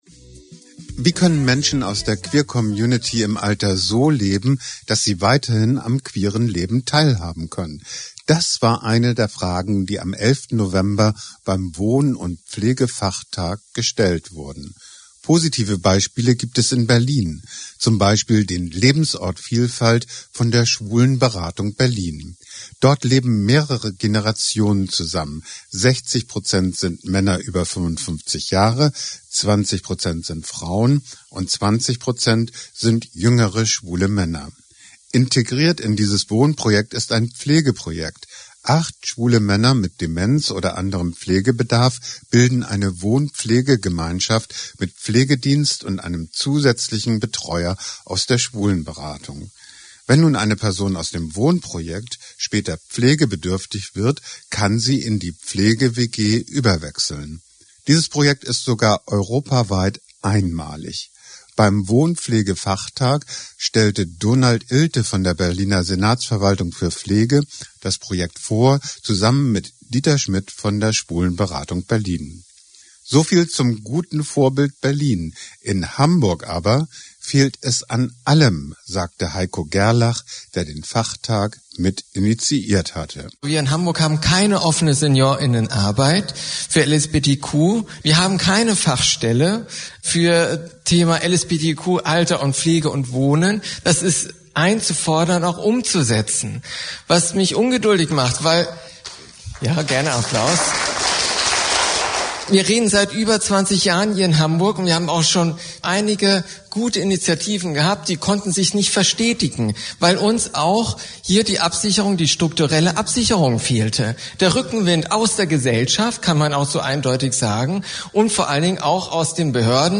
Sendung